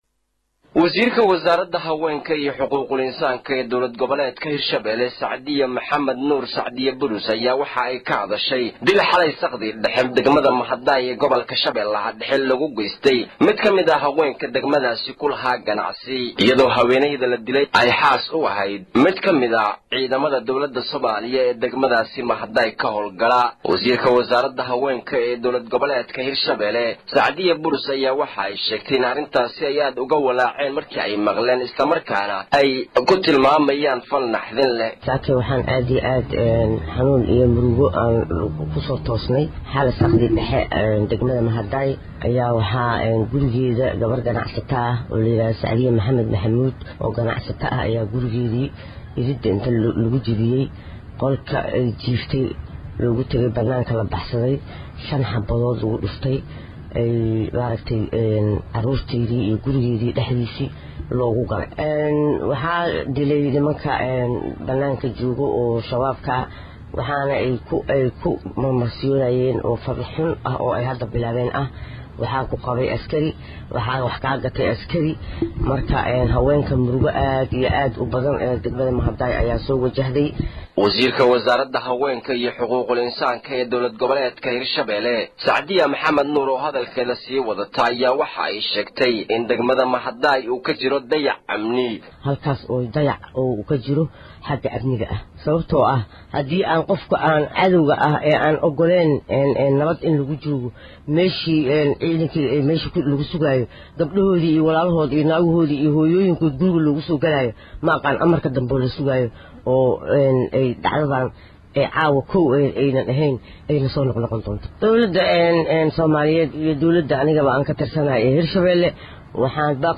Warar dheeraada ayaa laga helay haweenay argagixisadu ku gowracdey deegaanka Mahadaay. Wasiirada haweenka ee maamulka Hishabeele oo saxaafada la hadashay ayaa sheegtay in haweenaydaas argagixisadu gowracdey xalay saqdii dhexe.